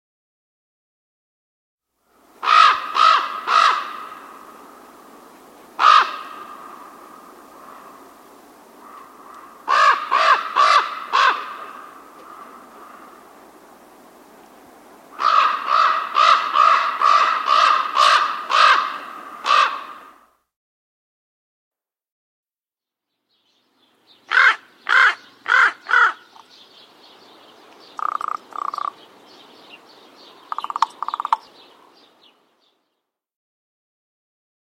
raven.mp3